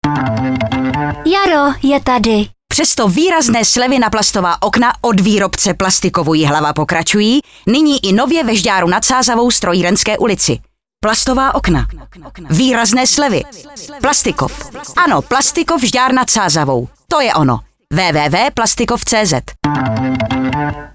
Slogan na radiu Vysočina č.6 [21.3.08]